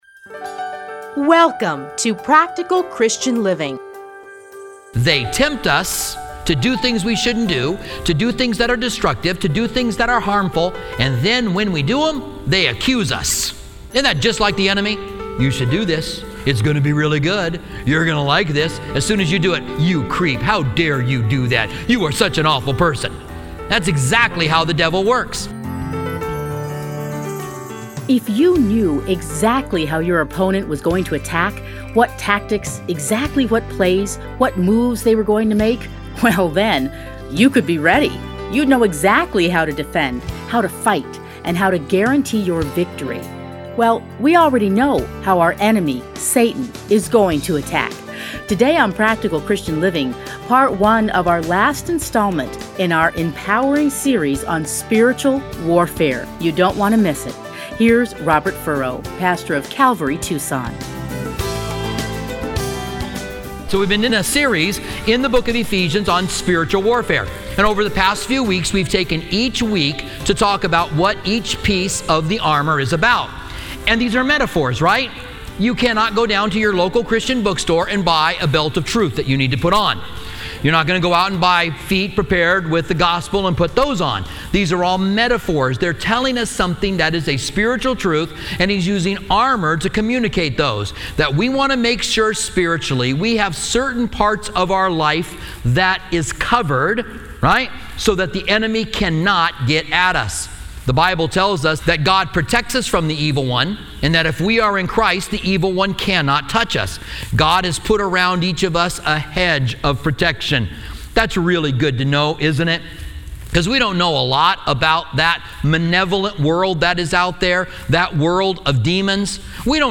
edited into 30-minute radio programs